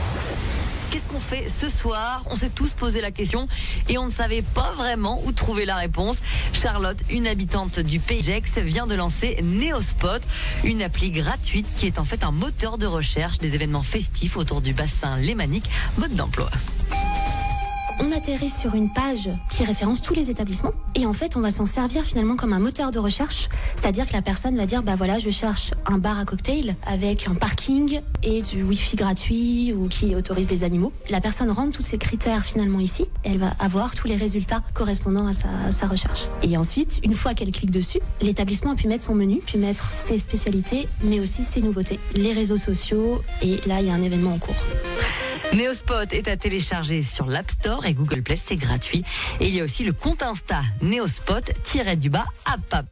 Flash Info 2